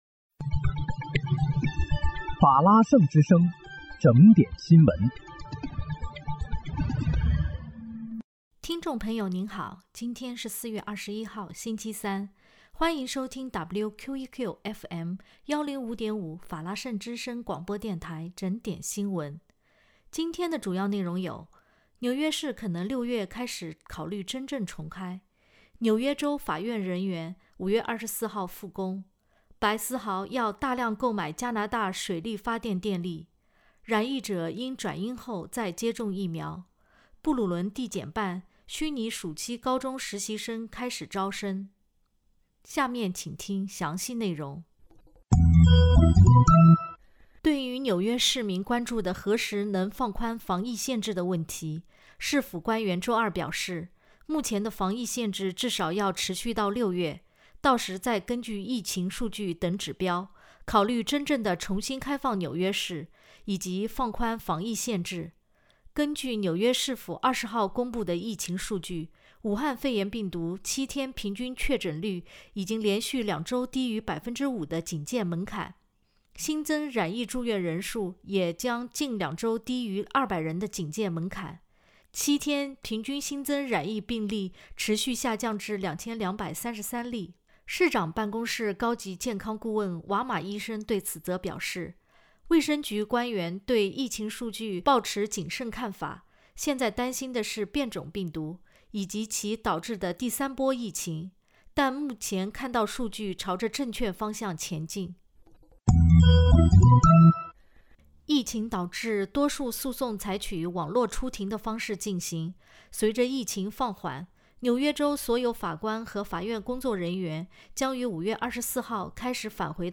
4月21日（星期三）纽约整点新闻